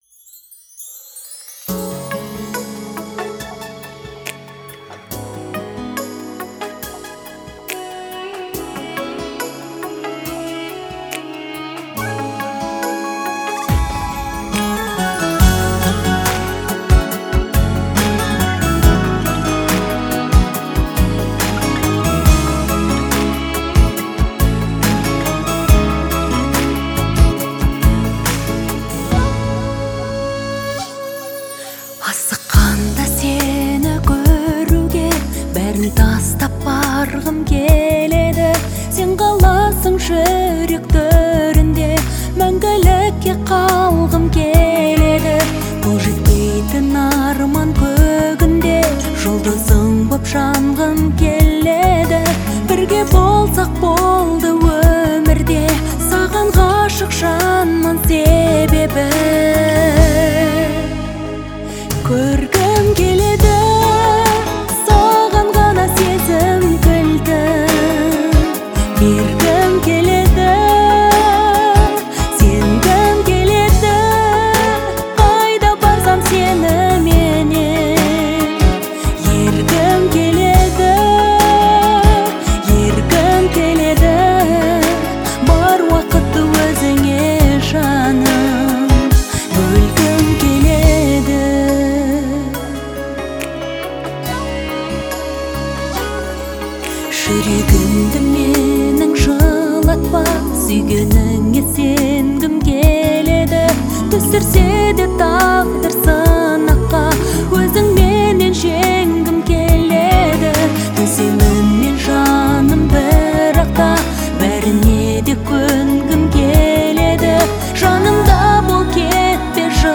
это яркая и эмоциональная песня в жанре казахской поп-музыки
Звучание отличается мелодичностью и выразительным вокалом